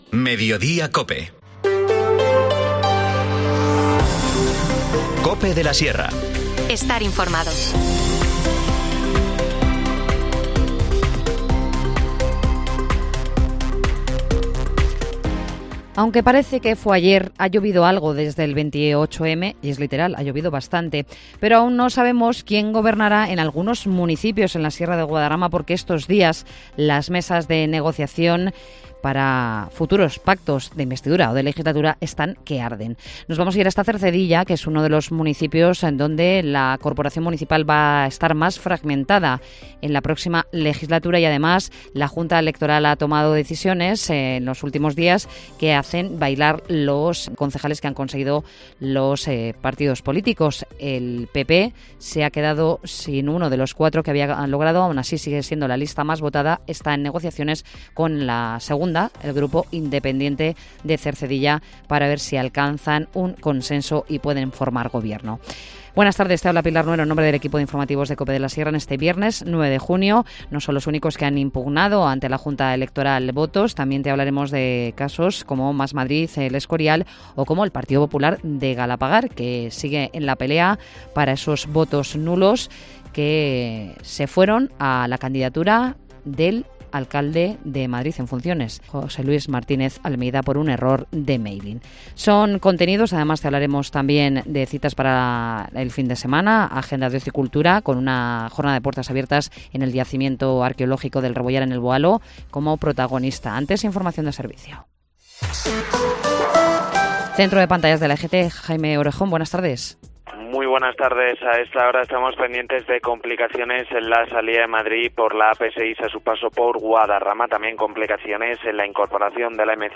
Informativo Mediodía 9 junio